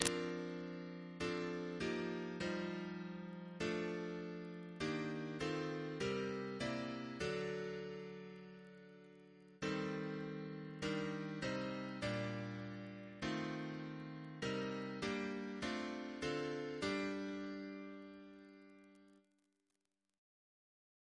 Double chant in G minor Composer